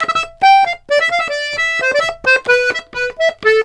Index of /90_sSampleCDs/USB Soundscan vol.40 - Complete Accordions [AKAI] 1CD/Partition C/04-130POLKA
S130POLKA4-R.wav